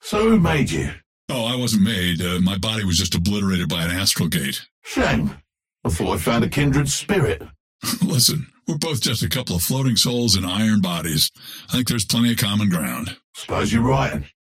Bebop and Dynamo conversation 1